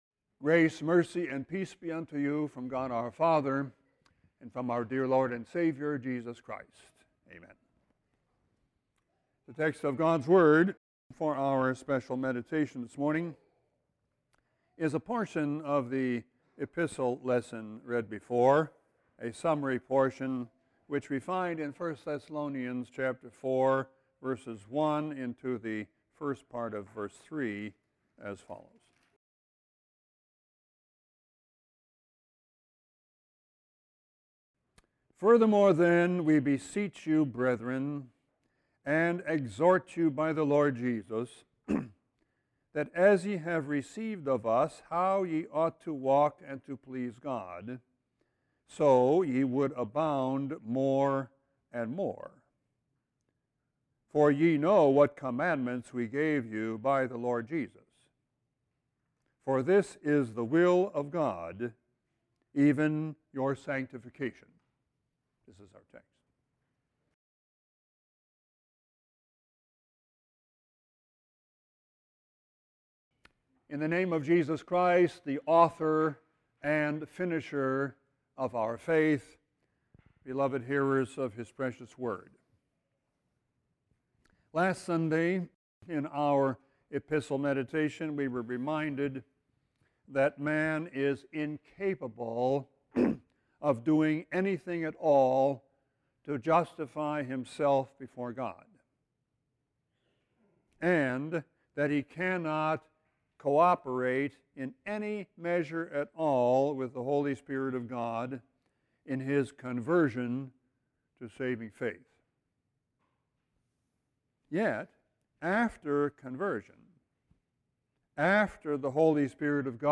Sermon 3-16-14.mp3